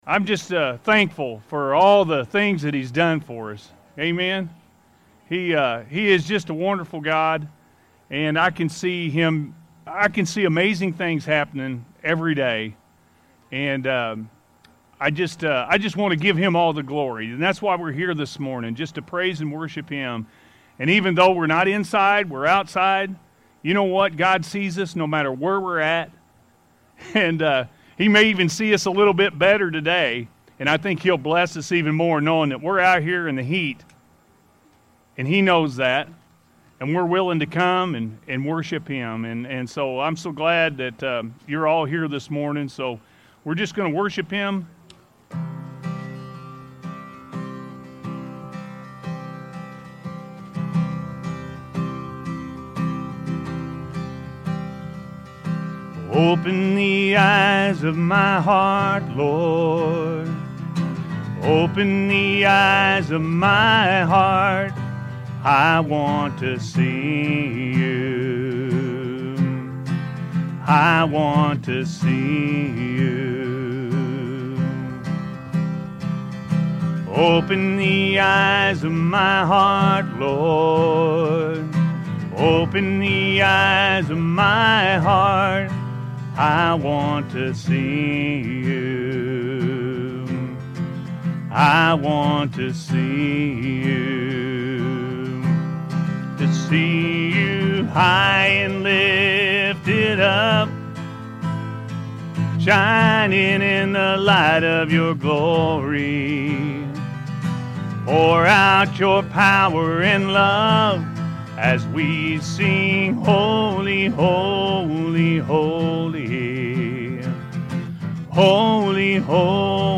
Give Careful Thoughts To Your Ways-A.M. Service